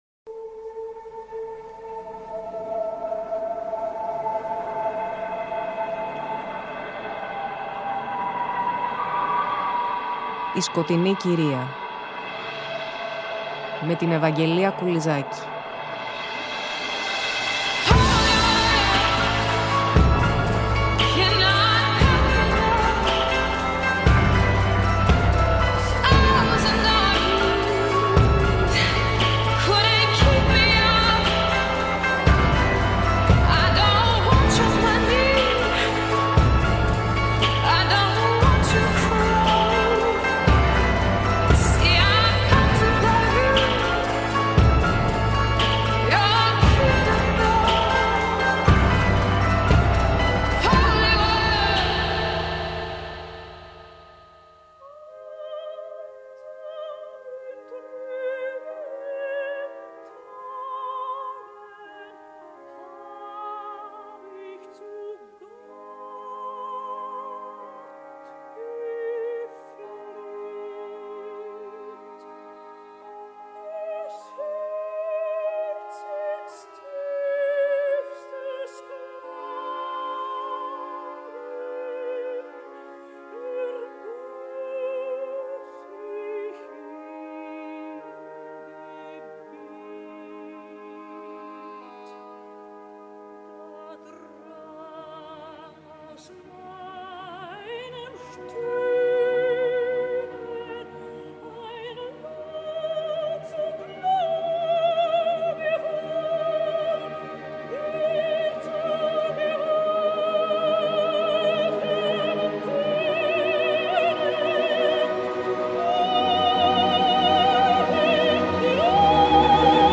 Στη συγκεκριμένη εκπομπή παρακολουθούμε την -κατά γενική παραδοχή- οξυδερκή και πρωτότυπη ερμηνευτική προσέγγιση της Σαλομέ στο έργο του Νίτσε, με μουσική πλαισίωση πρωτίστως βαγκνερική.